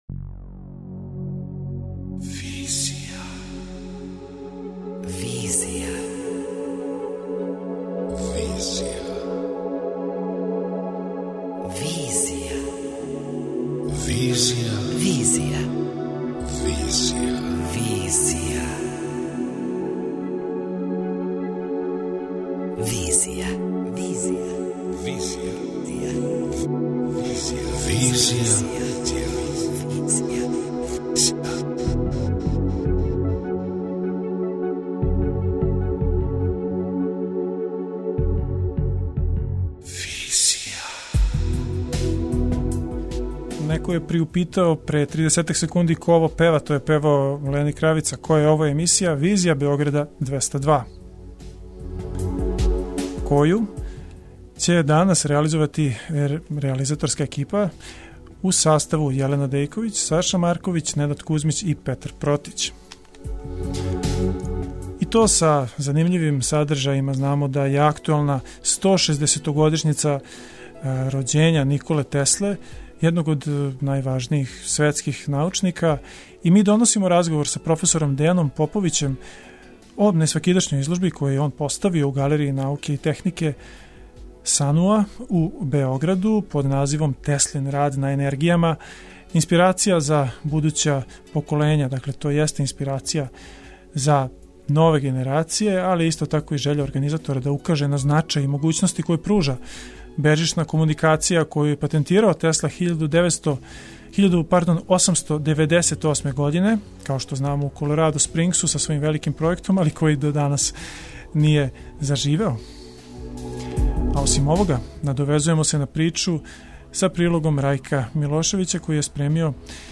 преузми : 26.82 MB Визија Autor: Београд 202 Социо-културолошки магазин, који прати савремене друштвене феномене.